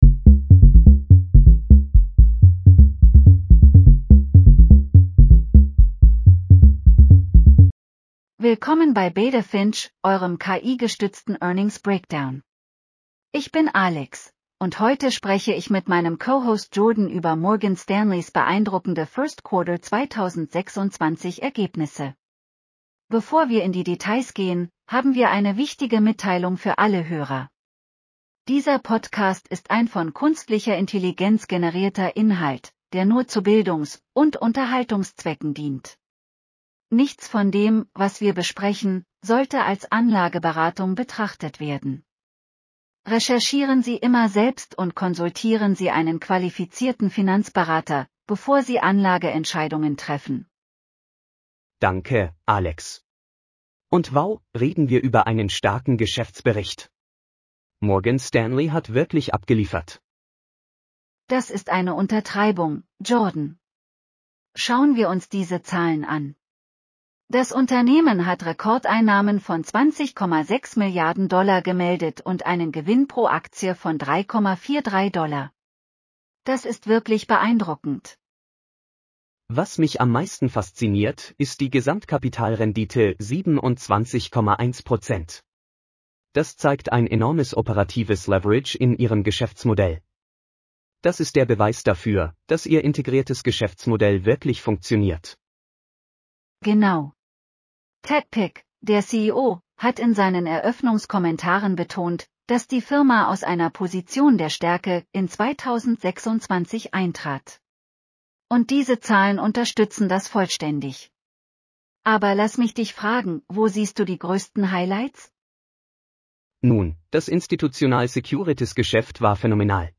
Willkommen bei Beta Finch, eurem KI-gestützten Earnings Breakdown!